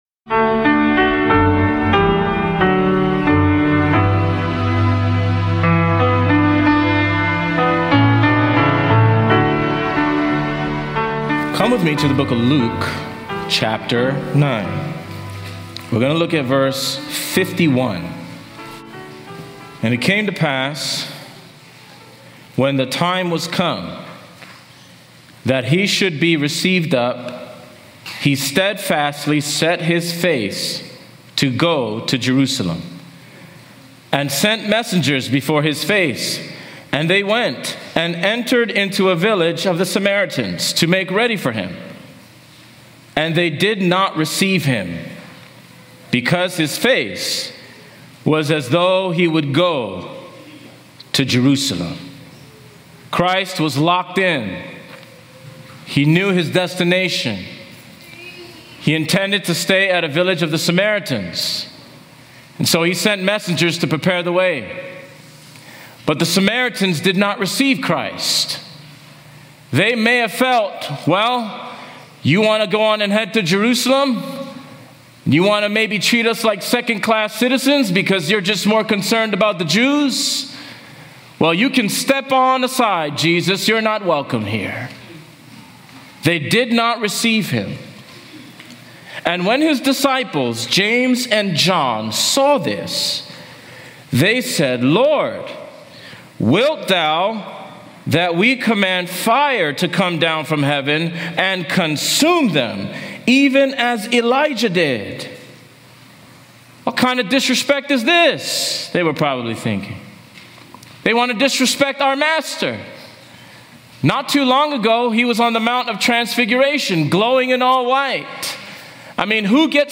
This powerful sermon challenges believers to follow Christ with mercy, focus, and sacrificial commitment, reflecting His mission to save rather than condemn. From the cost of discipleship to the call for Spirit-empowered action, it’s a compelling call to live out the gospel in both word and deed.